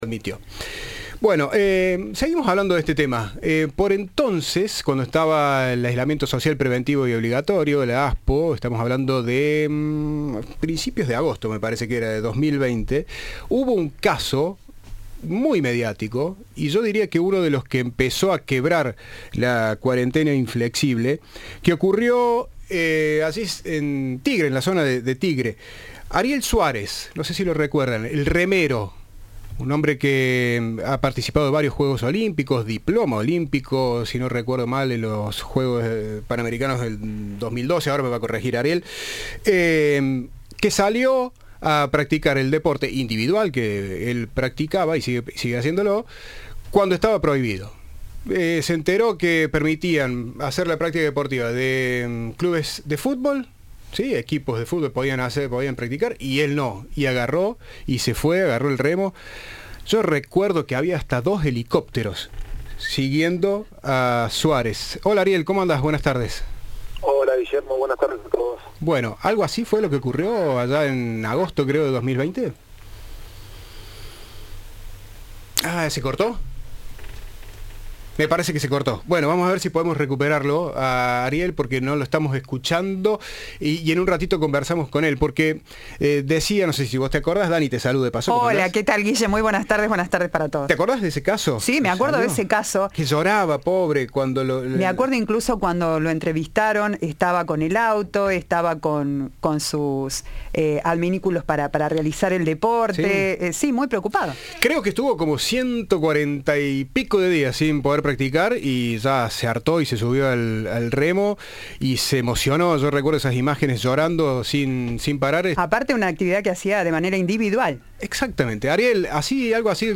En diálogo con Cadena 3, el campeón panamericano habló de aquel momento e hizo referencia al revuelo que causó el arreglo del Presidente por incumplir las restricciones en la fiesta en Olivos.